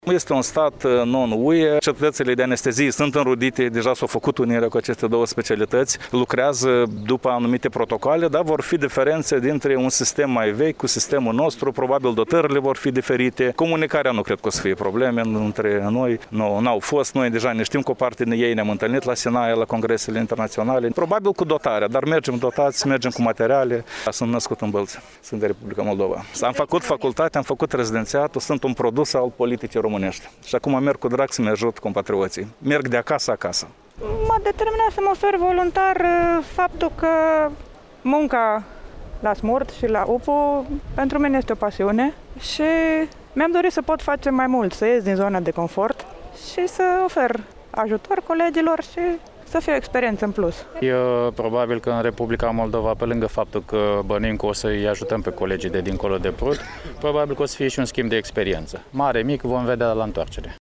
30-apr-rdj-12-vox-pop-voluntari.mp3